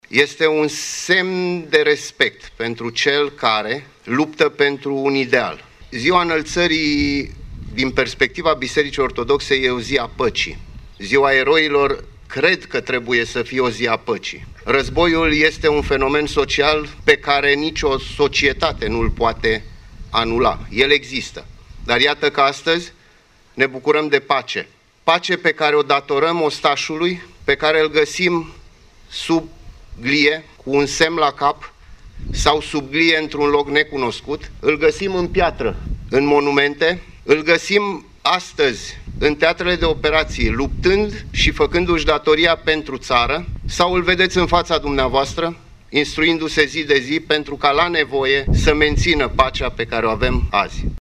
Autorităţi locale şi judeţene din Iaşi au marcat, astăzi, Ziua Eroilor, la monumentul din Cimitirul Eternitatea, la cel din dealul Galata şi la cimitirul eroilor căzuţi în al Doilea Război Mondial de la Leţcani.
Comandantul Garnizoanei Iaşi, Generalul de brigadă Remus Bondor, a vorbit despre importanţa zilei de astăzi: